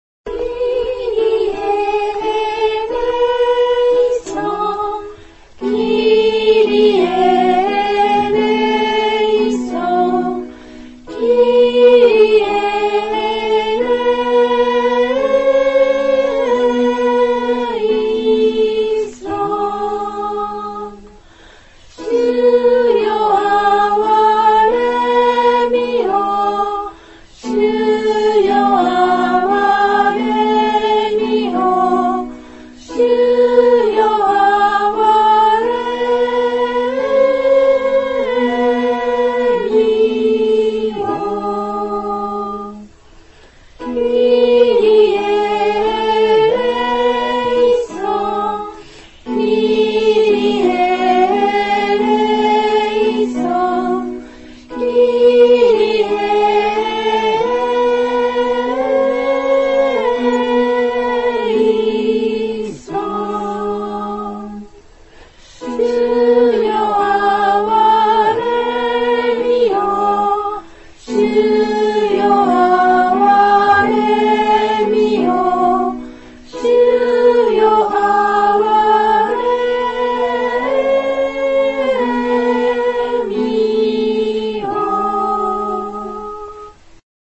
☆２回繰り返し ※「キリエ･エレイソン」は「主よ、あわれんでください」の意味。 マタ15:22, 20:30-31 礼拝 応答唱 ※視聴できない場合はをクリックしてください。